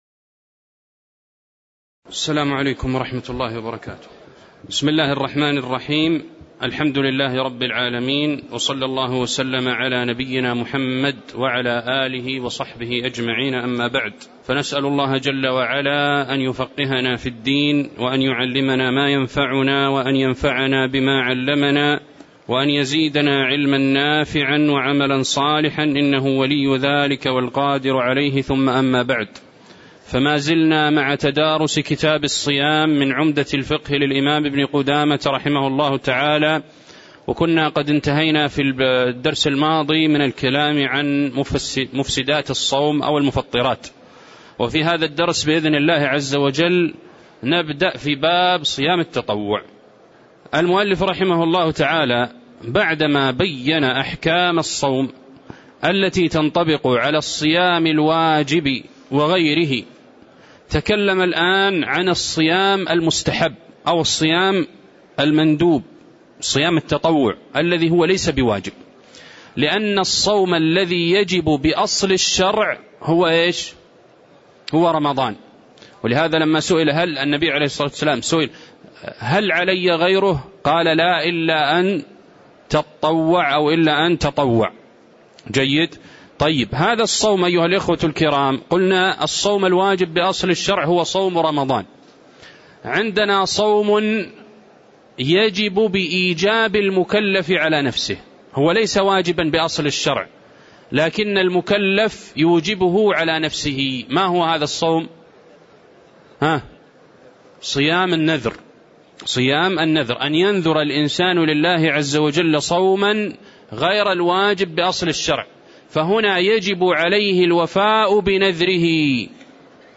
تاريخ النشر ٢٨ شعبان ١٤٣٧ هـ المكان: المسجد النبوي الشيخ